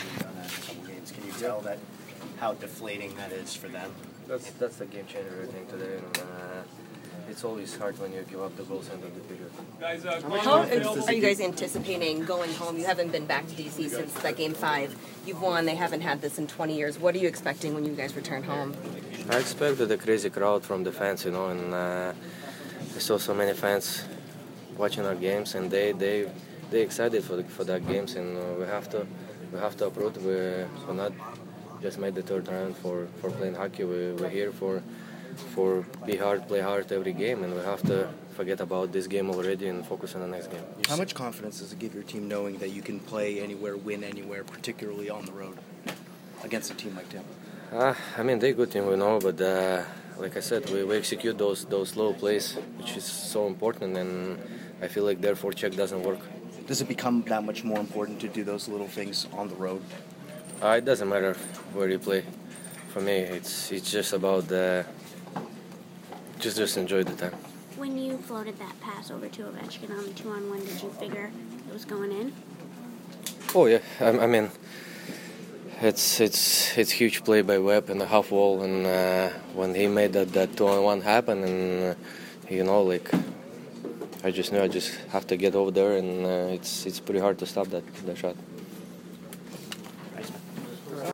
Evgeny Kuznetsov post-game 5/13